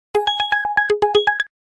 Звуки таксометра
Оповещение о новом заказе в Яндекс.Такси